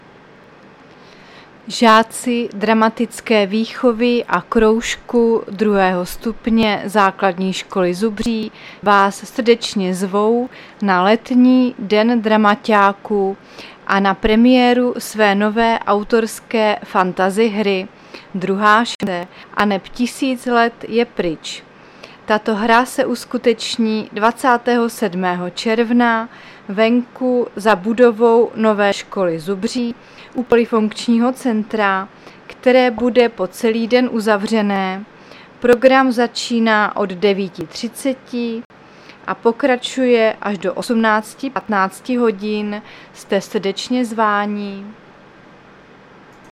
Záznam hlášení místního rozhlasu 27.6.2023